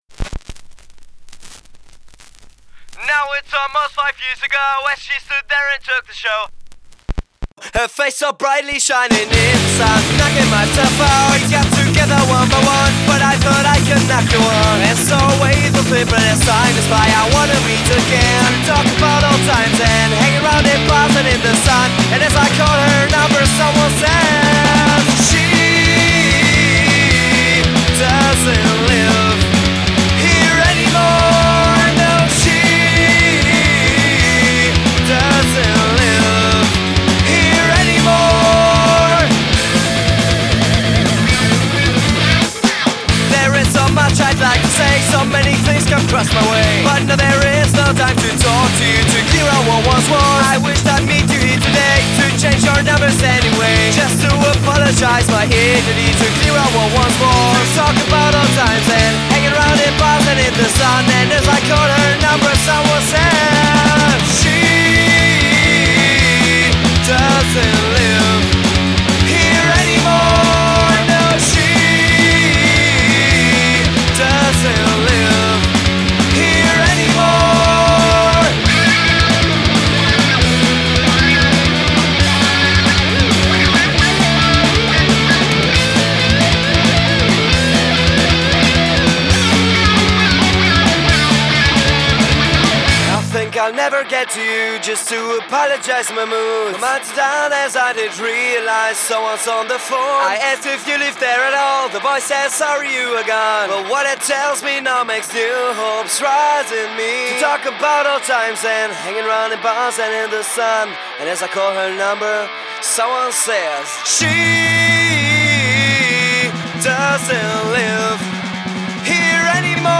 Ihre schnellen Riffs und die eingängigen Melodien